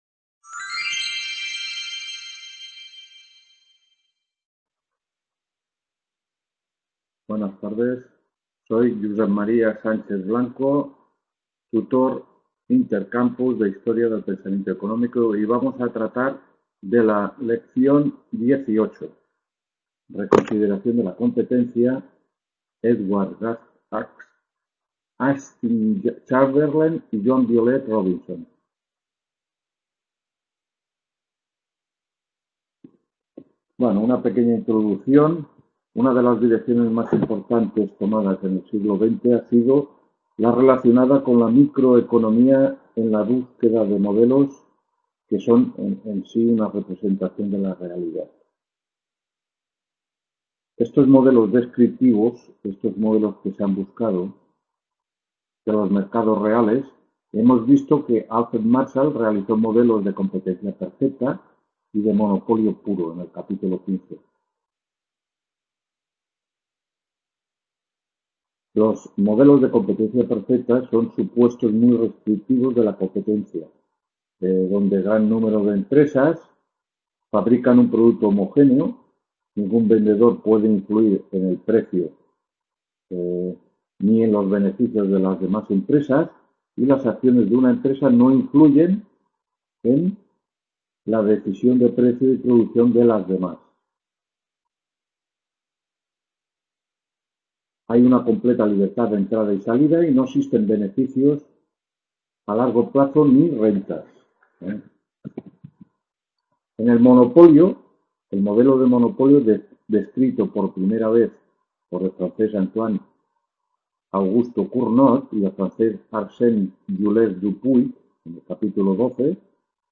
4ª (II) TUTORÍA CHAMBERLIN Y ROBINSON HISTORIA…